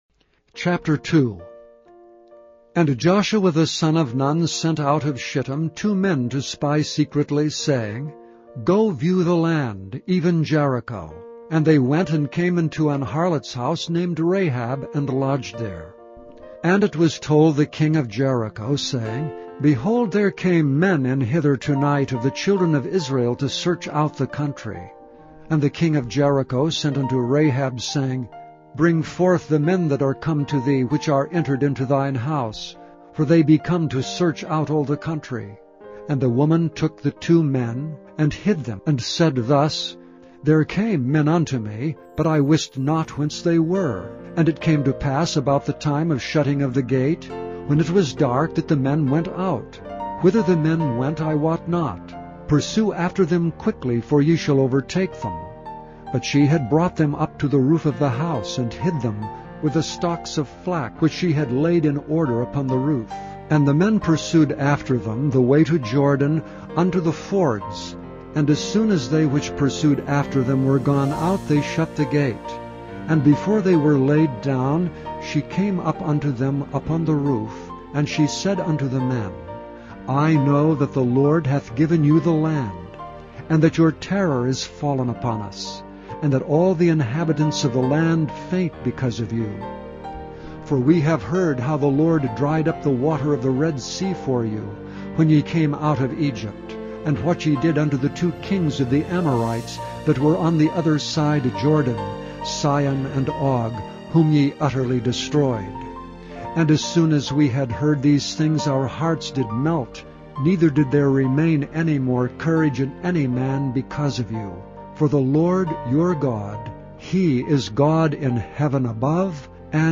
Listen to Joshua 2 Listen to Joshua chapter two being read, or download it to listen to later.